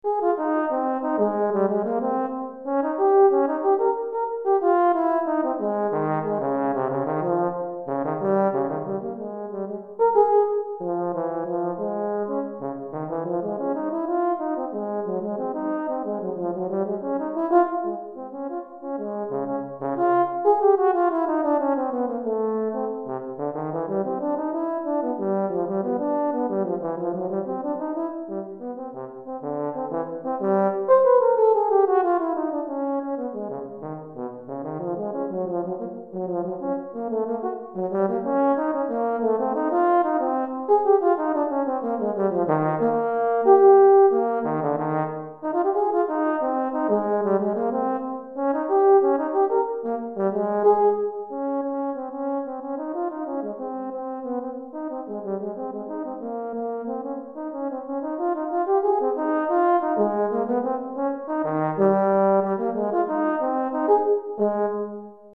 Etude pour Cor - Cor en Fa Solo